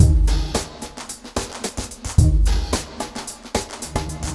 RemixedDrums_110BPM_49.wav